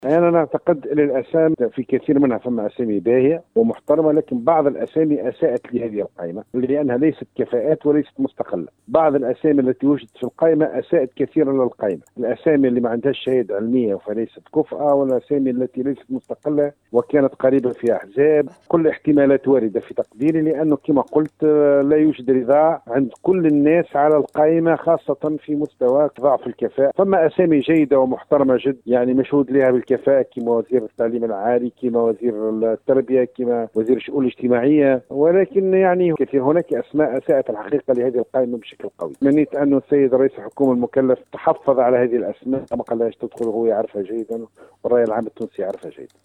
Dans une déclaration accordée à Tunisienumérique, le député, Mabrouk Korchide, a estimé que certains ministres proposés par Mechichi « ne sont ni compétents ni indépendants ».
Déclaration de Mabrouk Korchide